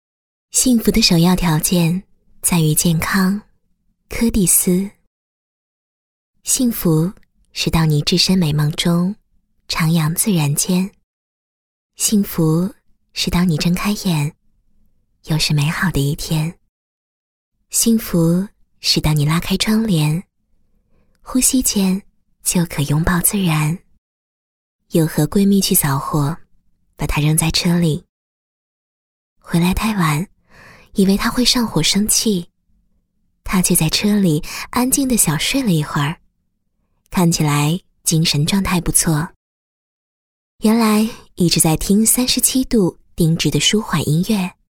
女粤17_动画_童声_钱塘江春行男童
配音风格： 年轻 亲切 欢快 时尚 活力 配音语言： 粤语